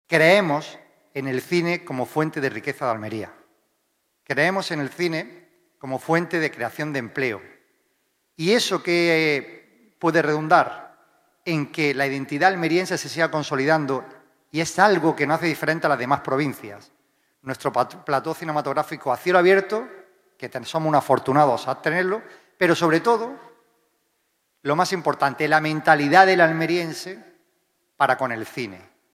El Patio de Luces de Diputación ha acogido la presentación de la XXIV edición de FICAL, impulsado por la Institución Provincial, con el apoyo del Ayuntamiento de Almería y la Junta de Andalucía
JAVIER-AURELIANO-GARCIA-PRESIDENTE-DIPUTACION-PRESENTACION-FICAL-2025.mp3